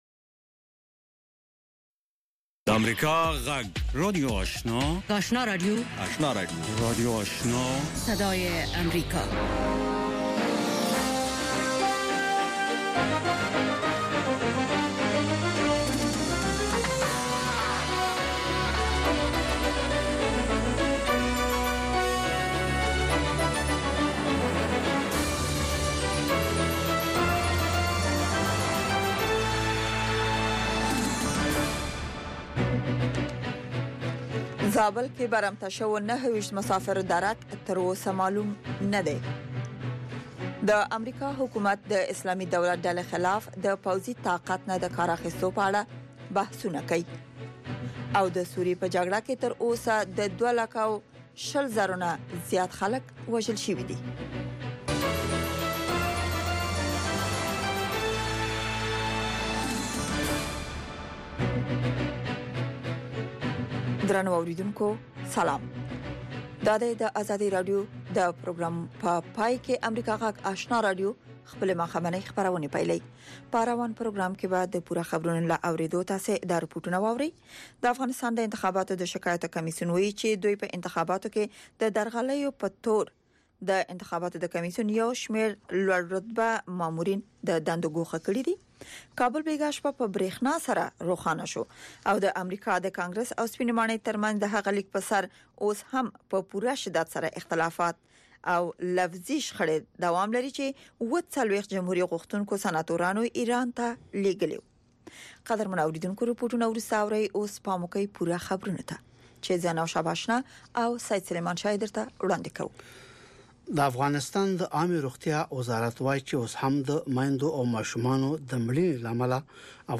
ماښامنۍ خبري خپرونه